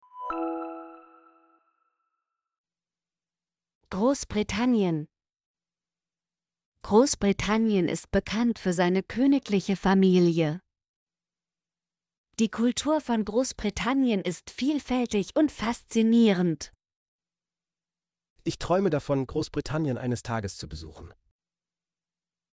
großbritannien - Example Sentences & Pronunciation, German Frequency List